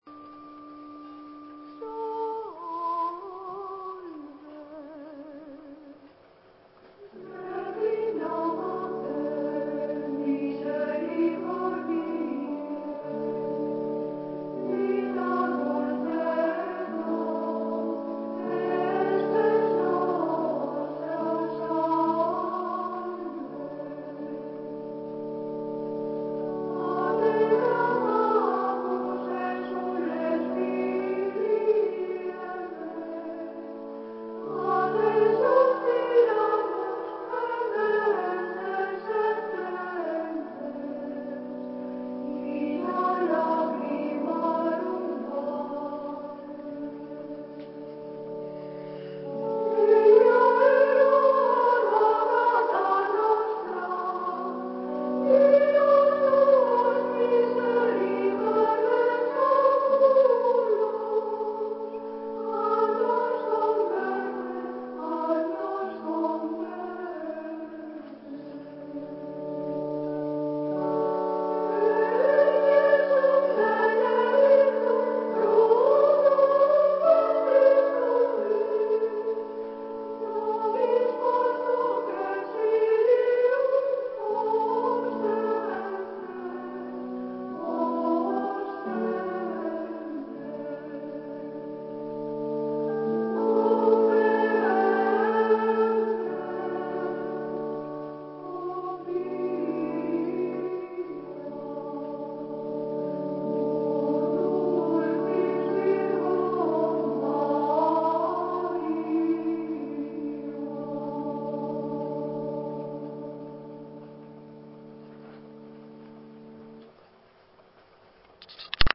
Salve-imitacion-gregoriana.mp3